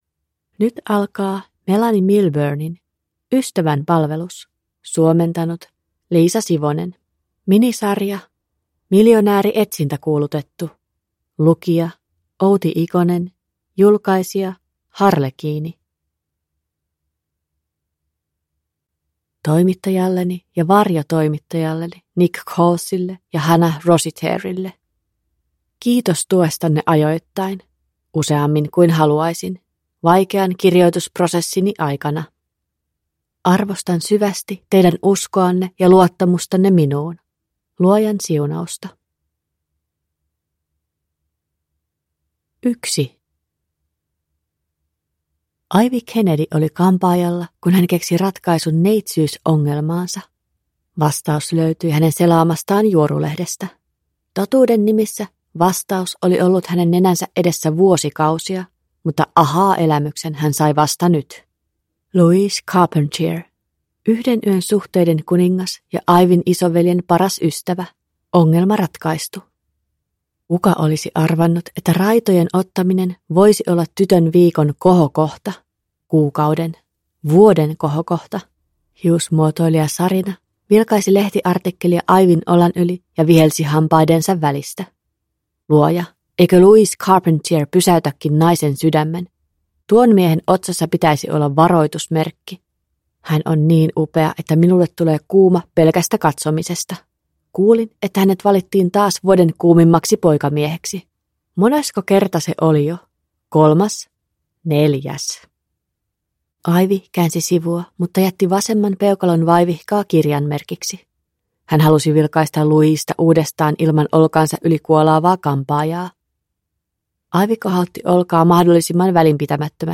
Ystävänpalvelus (ljudbok) av Melanie Milburne